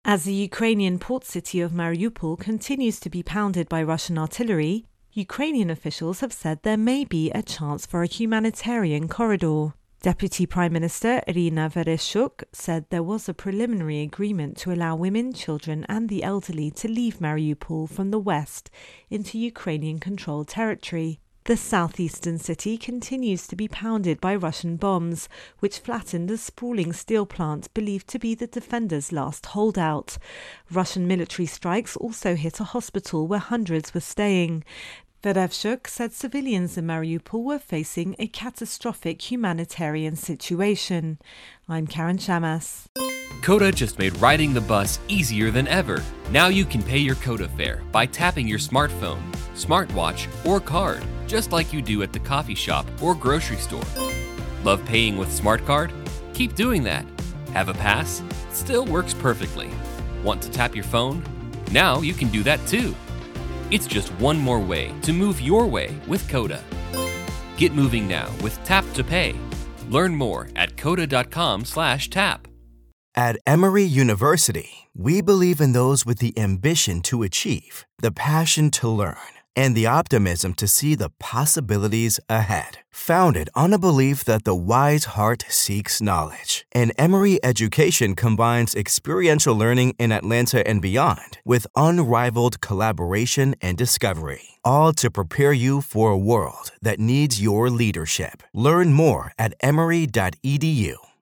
Russia Ukraine War Mariupol Intro and Voicer